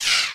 emz_dryfire_01.ogg